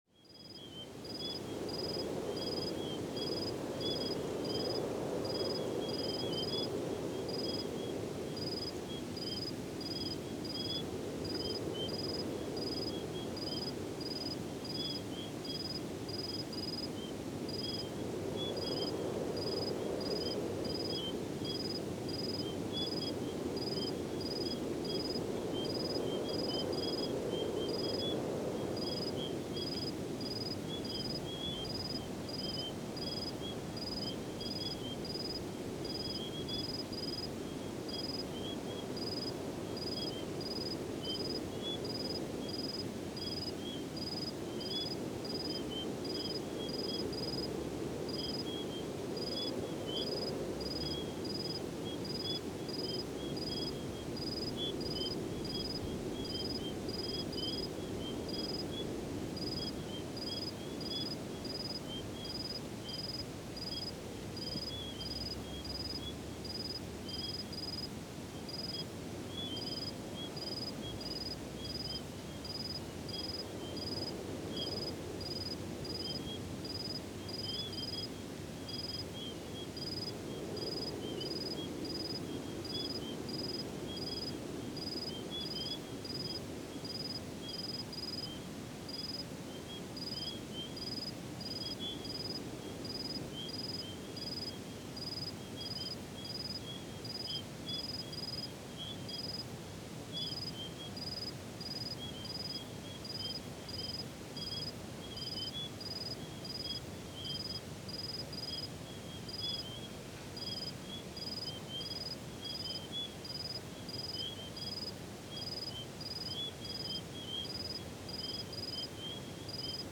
‘Desert at Night’, field recording, Northern Territory, Australia, 2011
07-Sound-Desert-at-Night.mp3